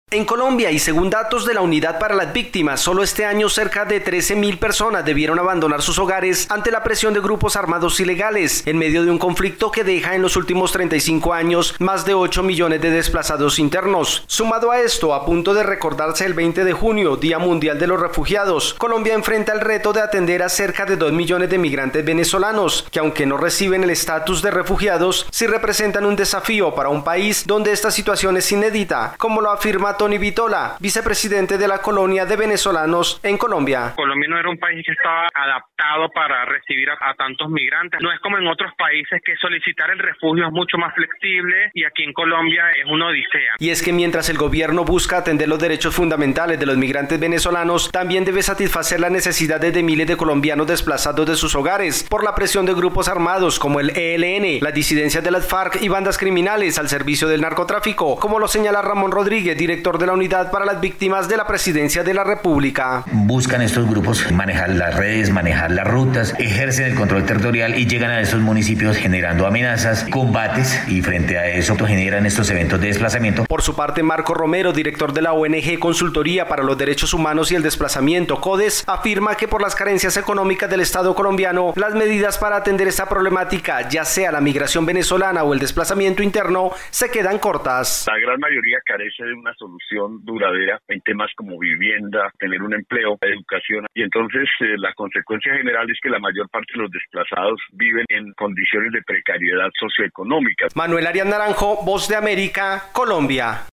Colombia enfrenta una doble problemática con los refugiados, con ocho millones de desplazados internos y cerca de dos millones de migrantes venezolanos. Desde Colombia informa el corresponsal de la Voz de América